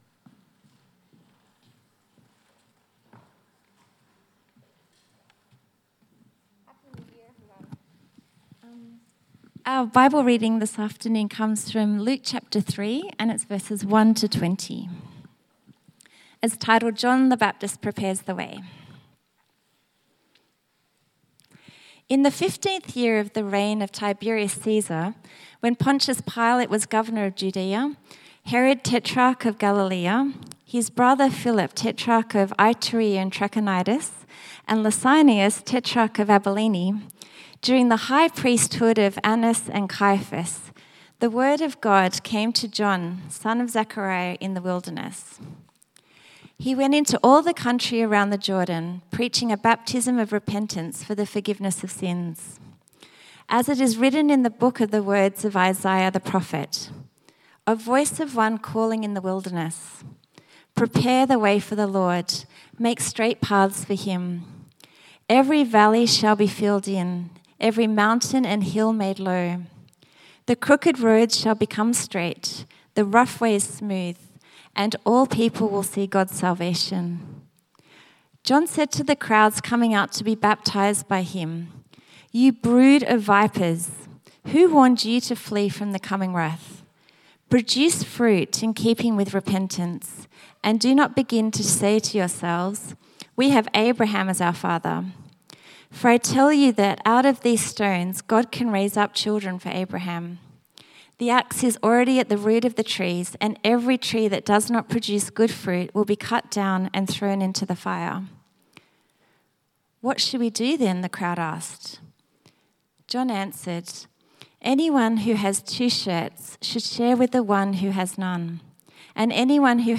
Service Type: 4PM